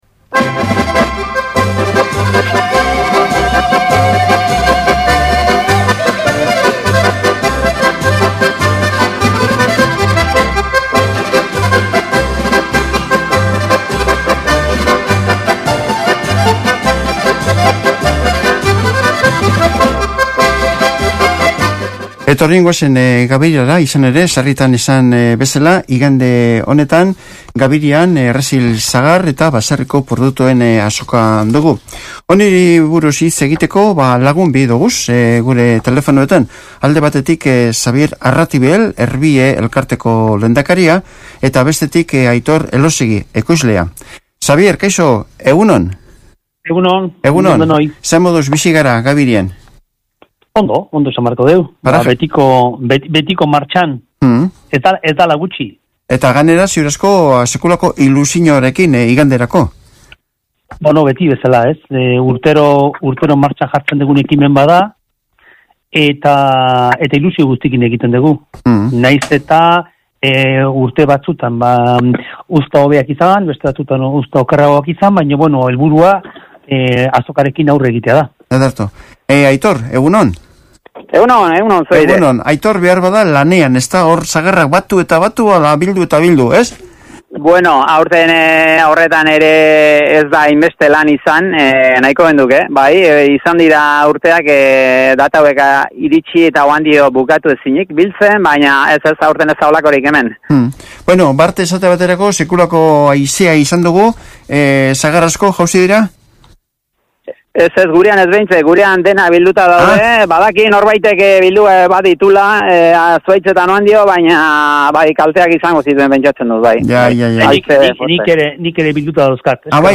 Alkarrizketa